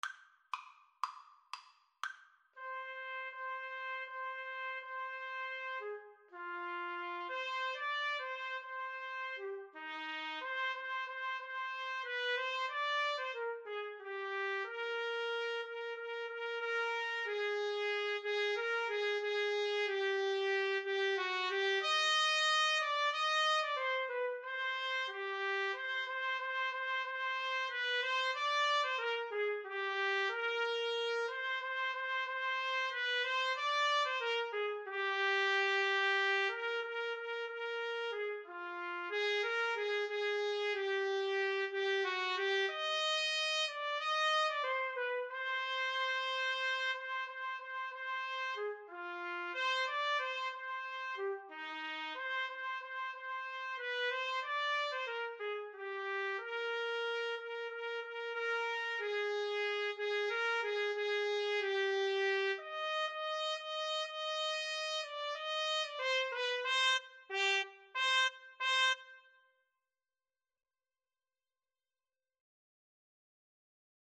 Play (or use space bar on your keyboard) Pause Music Playalong - Player 1 Accompaniment reset tempo print settings full screen
Eb major (Sounding Pitch) F major (Trumpet in Bb) (View more Eb major Music for Trumpet-Trombone Duet )
Steadily (first time) =c.60